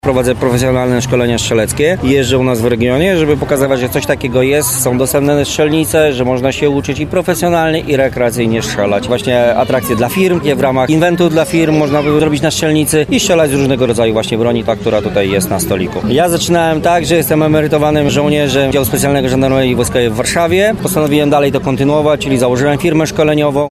Festyn Pozytywnie Zakręconych